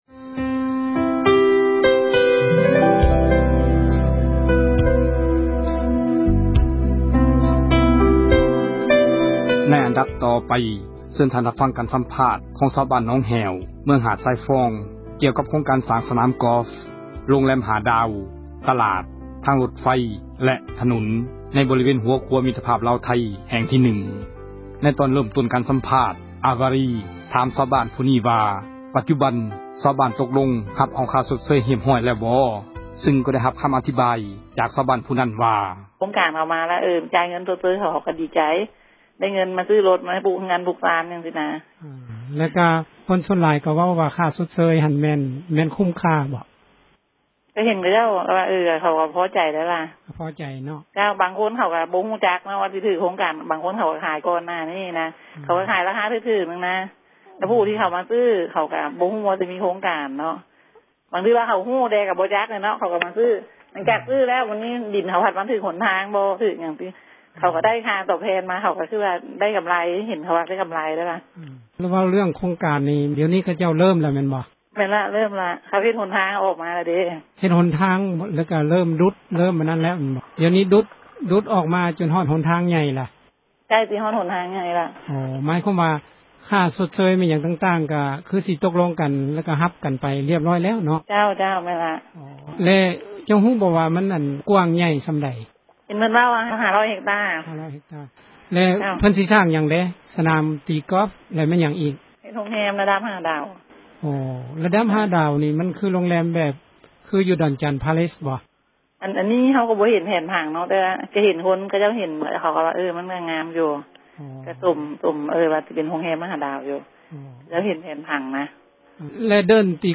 ການສັມພາດ ຊາວບ້ານໜອງແຫ້ວ ເມືອງຫາດຊາຍຟອງ